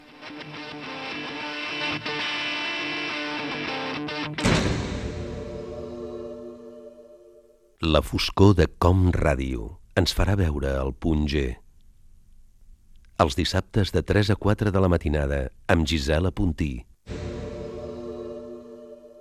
Promoció del programa de matinada